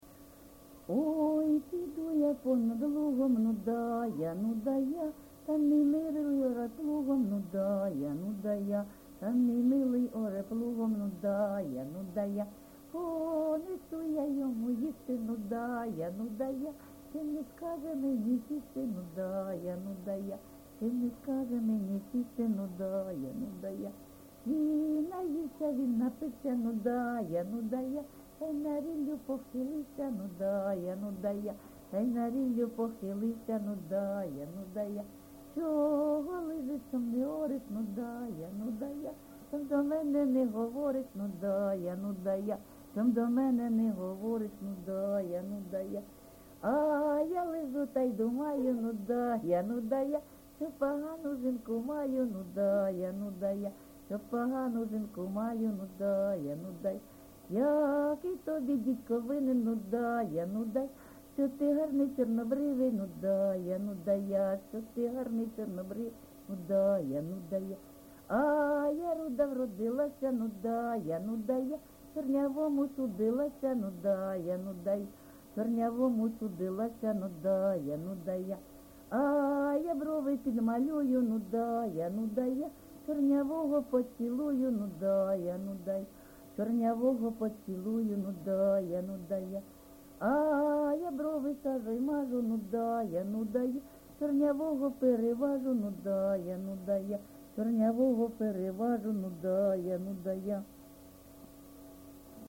ЖанрПісні з особистого та родинного життя, Жартівливі
Місце записум. Бахмут, Бахмутський район, Донецька обл., Україна, Слобожанщина